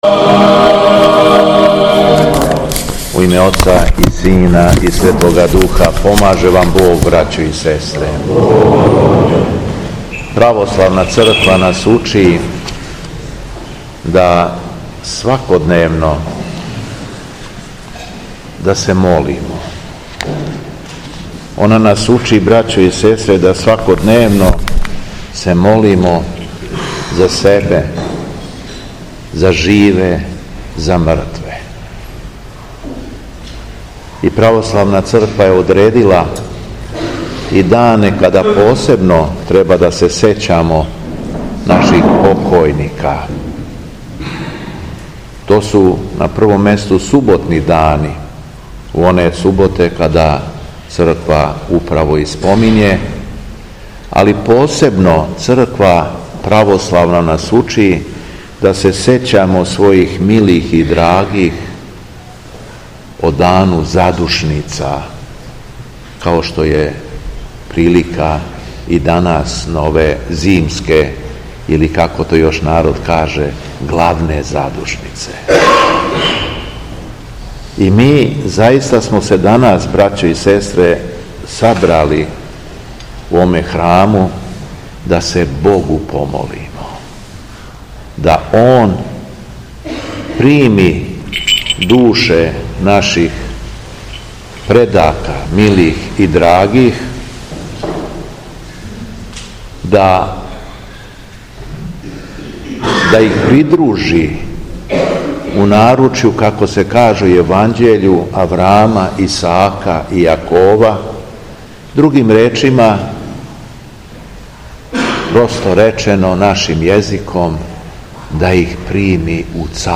У суботу, 22. фебруара 2025. године Његово Високопреосвештенство Архиепископ крагујевачки и Митрополит шумадијски Господин Јован служио је Свету архијерејску Литургију у храму Свете Петке у Смедеревској Паланци, архијерејско намесништво јасеничко.
Беседа Његовог Високопреосвештенства Митрополита шумадијског г. Јована
Након прочитаног зачала из Светог Јеванђеља Високопреосвећени Владика се обратио верном народу рекавши: